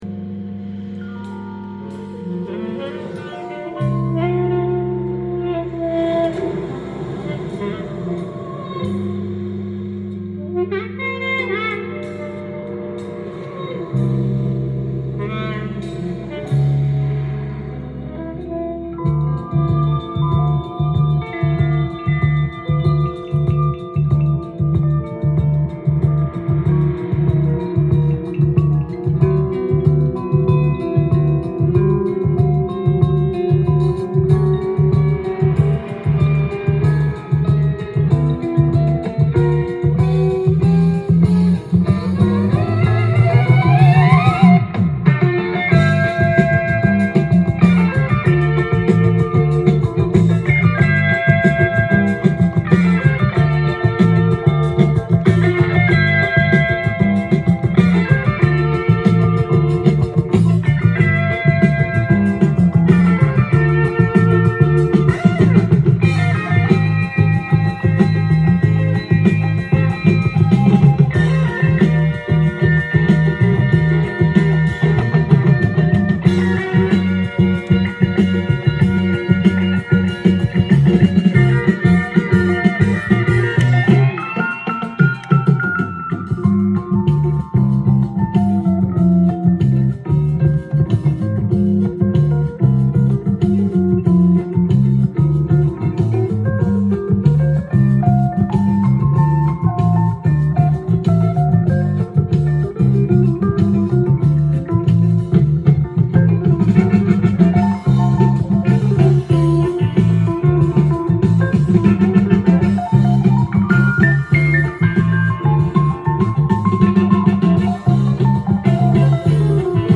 ジャンル：ROCK & POPS
店頭で録音した音源の為、多少の外部音や音質の悪さはございますが、サンプルとしてご視聴ください。